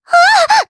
Cleo-Vox_Damage_jp_01.wav